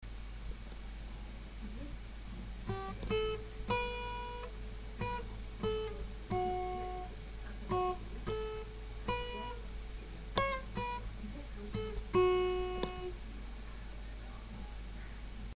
Вниз  Играем на гитаре
Появилась какая-то техника, скорость.